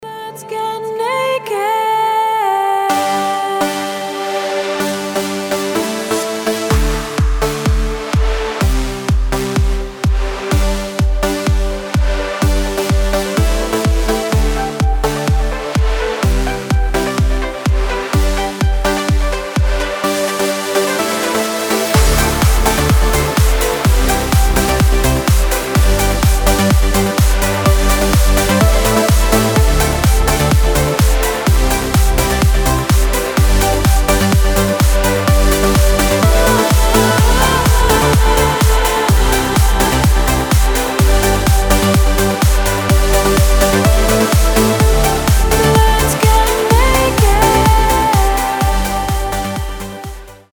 • Качество: 320, Stereo
ритмичные
громкие
женский вокал
dance
Electronic
progressive trance